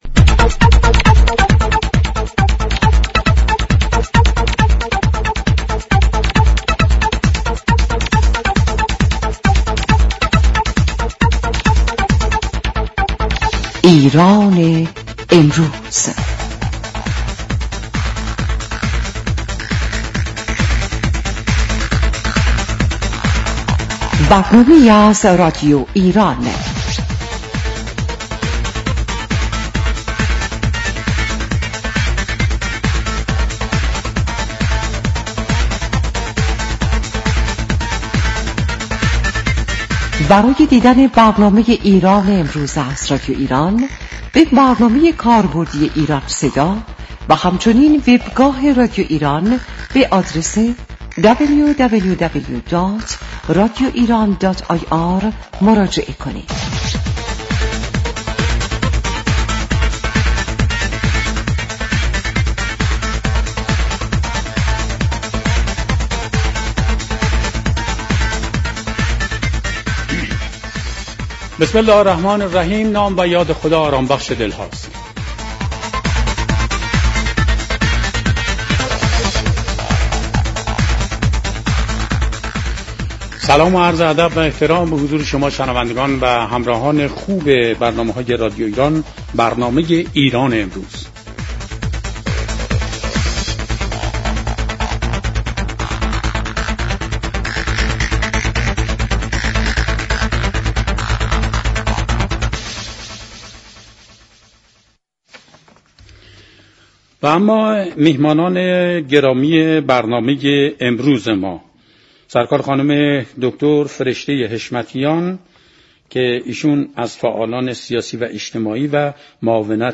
گفت و گوی رادیویی